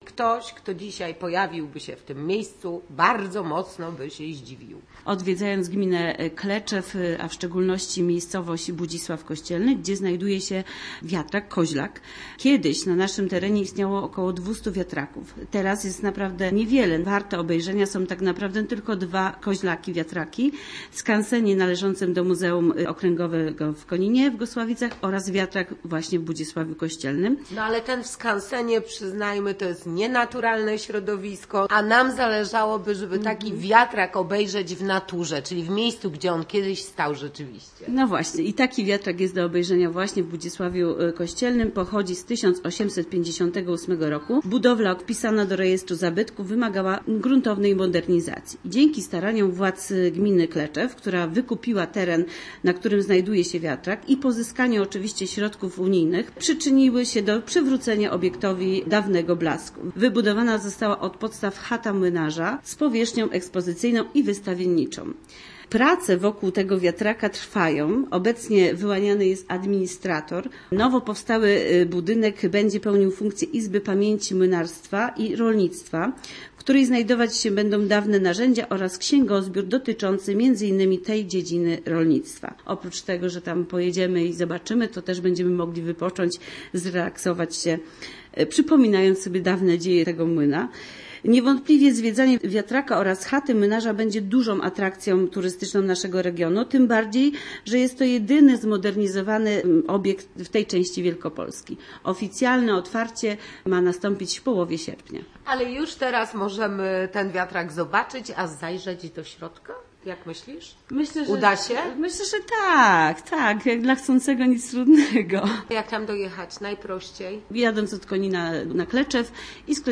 0jiqu8pagsih401_wiatrak_kleczew_rozmowa.mp3